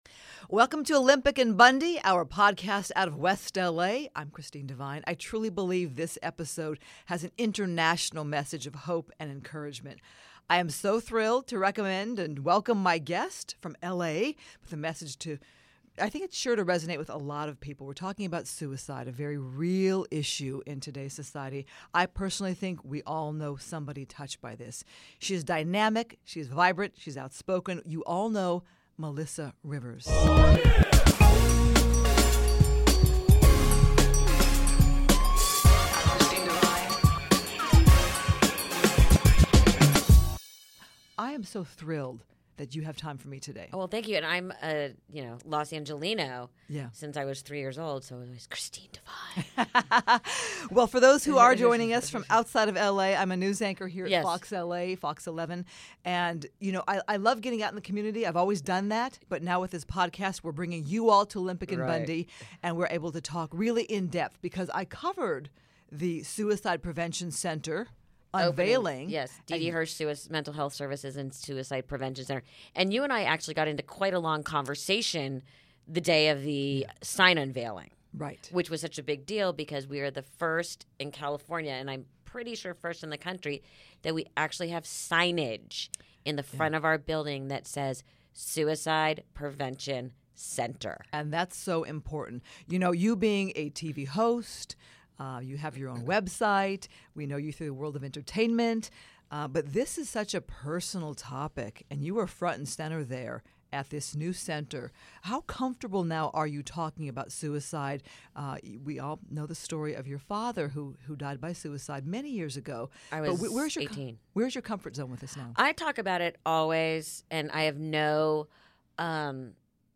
72 - Melissa Rivers, a candid conversation on suicide prevention